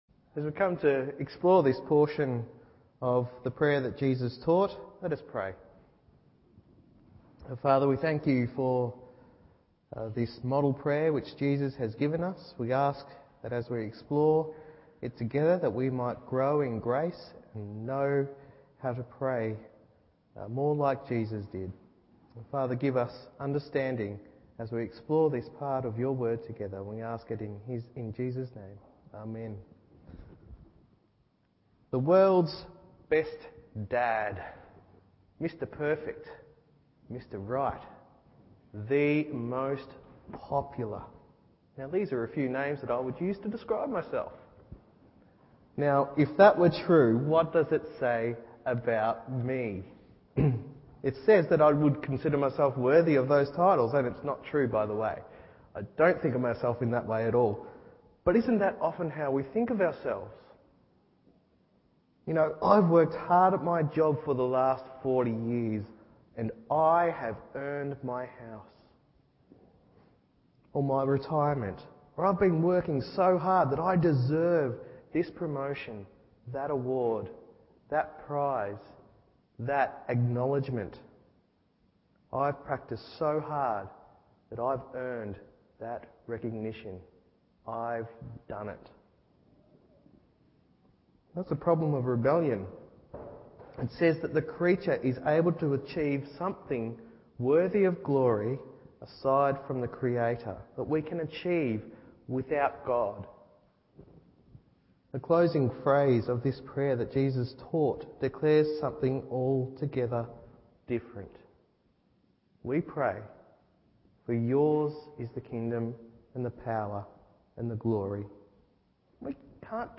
Evening Service Matt 6:5-13 1.